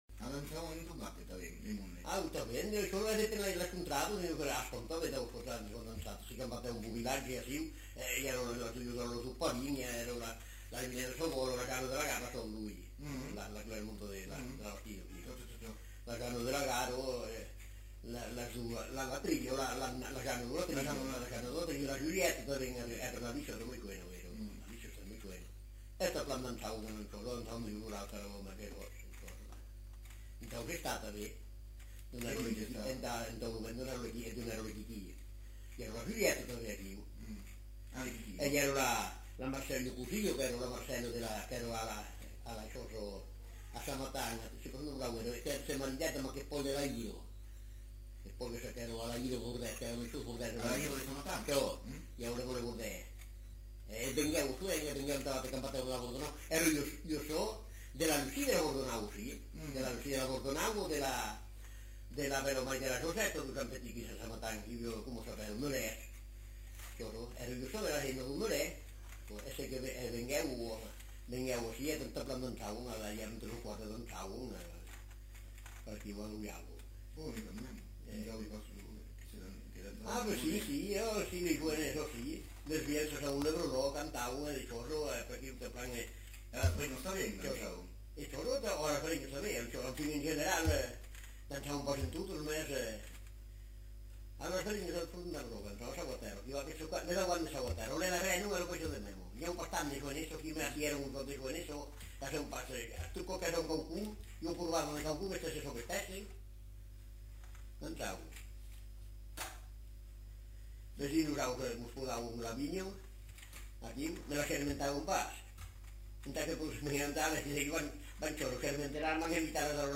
Lieu : Espaon
Genre : témoignage thématique
Instrument de musique : accordéon diatonique